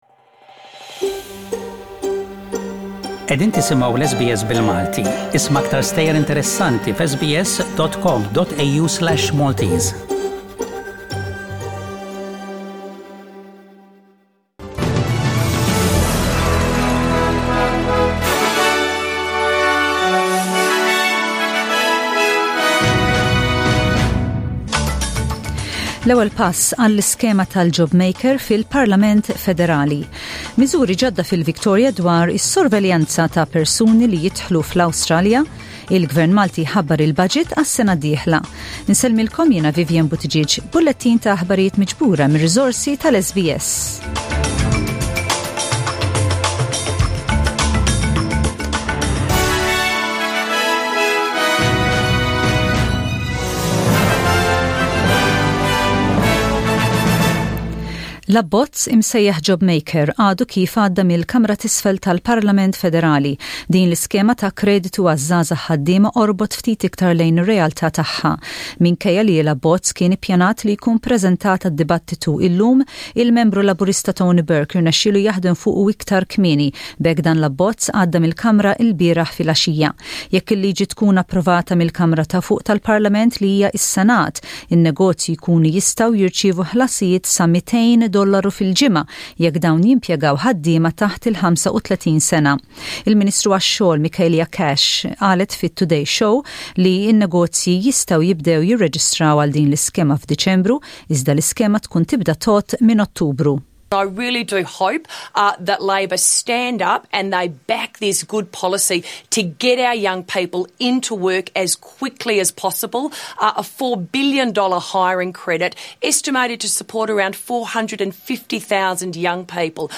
SBS Radio | Aħbarijiet bil-Malti: 20/10/20